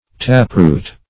Taproot \Tap"root`\, n. (Bot.)